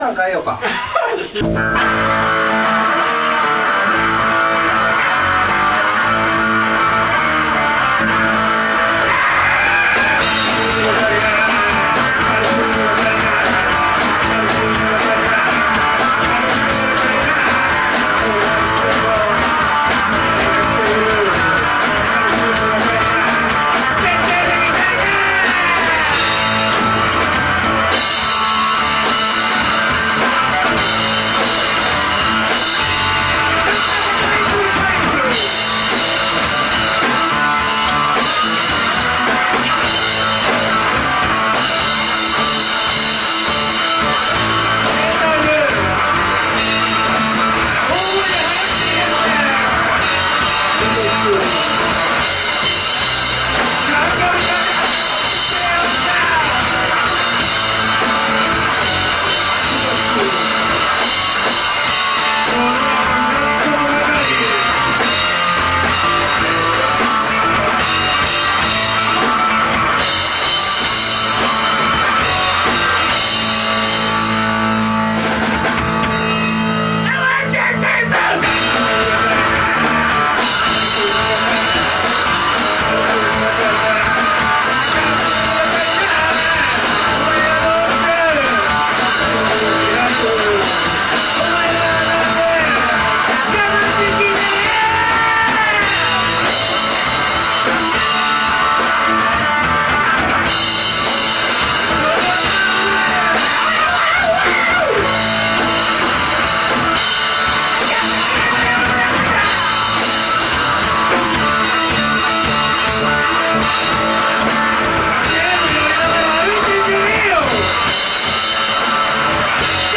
スタジオ録音